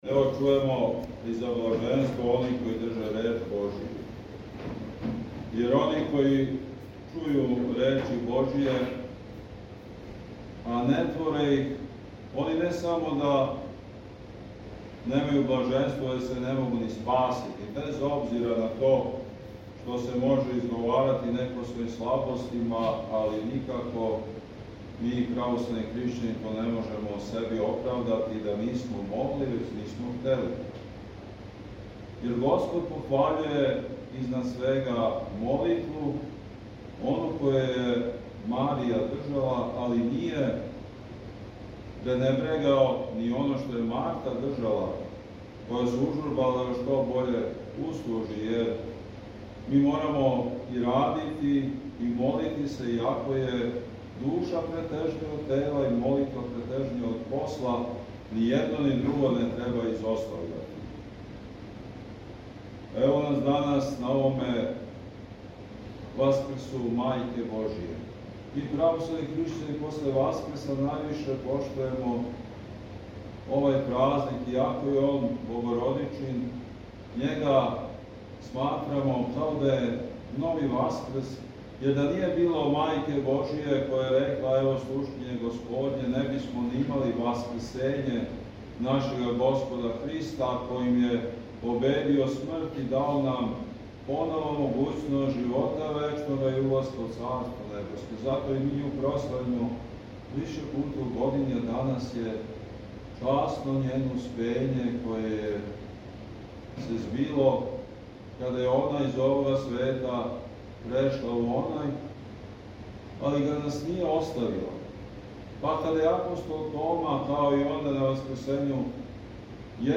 беседа на Успење Пресвете Богородице